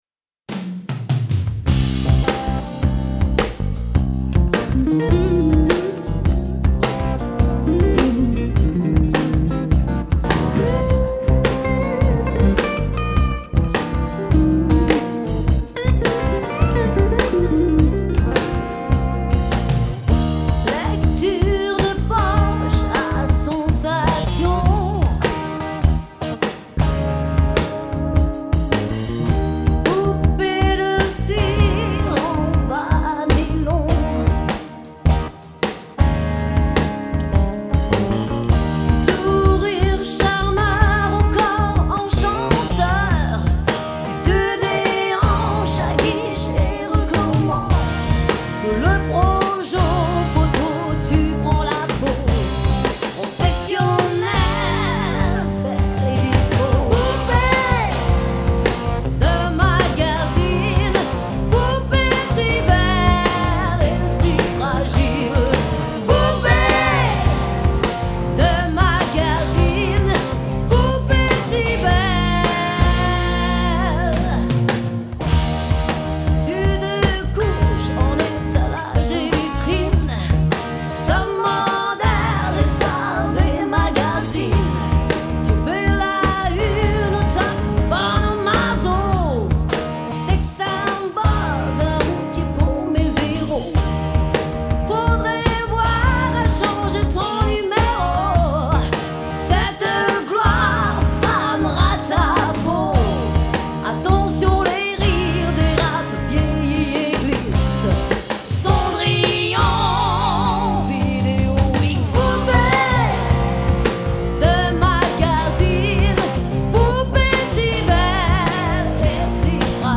Enregistré & mixé au Studio Helios XVI
batterie, percussions, choeurs, programmations
basse
guitares
trompette
saxophone
claviers